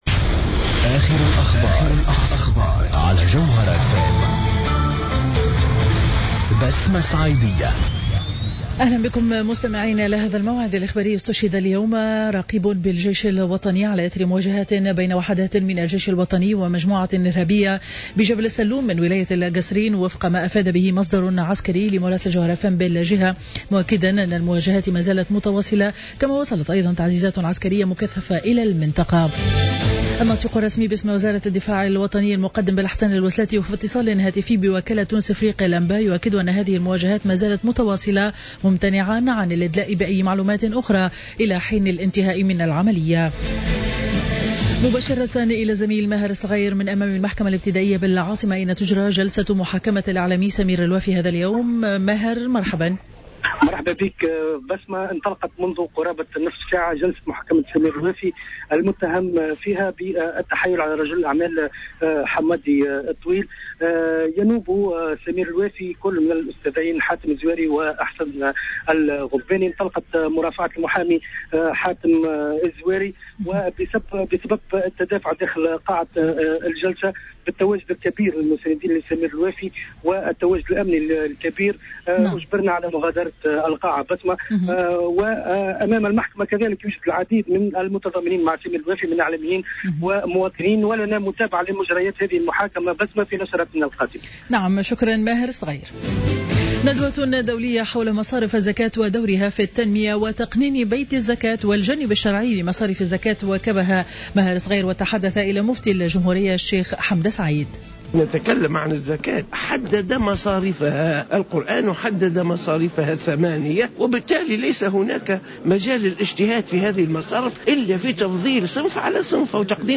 نشرة أخبار منتصف النهار ليوم الأربعاء 22 أفريل 2015